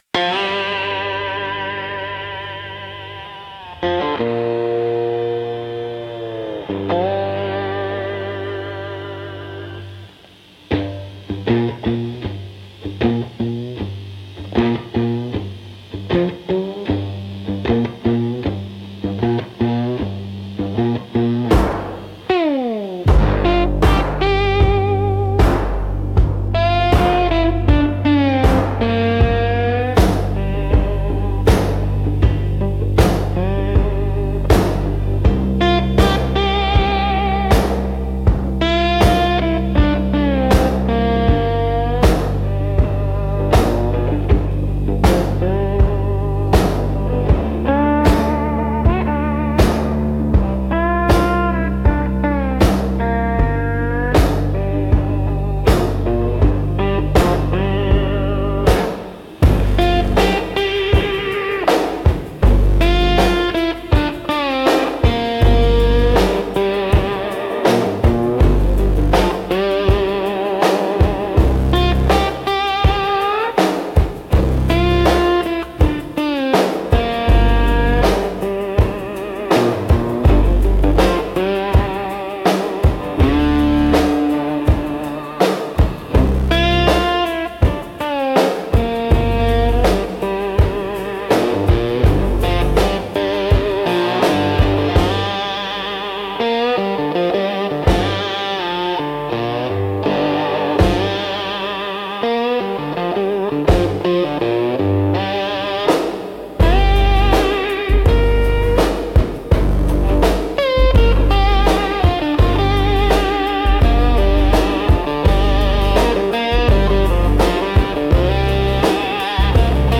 Instrumental - Ashes at the Crossroads